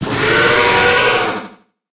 Cri d'Hydragon dans Pokémon HOME.